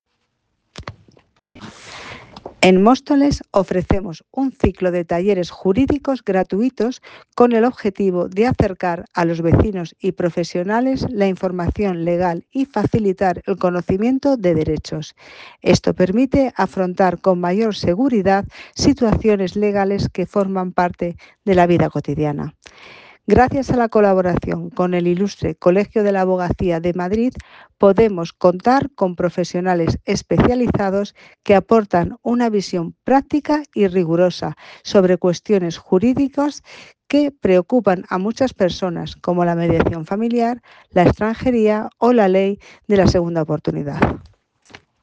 Audio - Ángeles García González (Concejal de Economía, Industria y Empleo) - Talleres Jurídicos
Audio - Concejal de Economía, Industria y Empleo - Talleres Jurídicos.m4a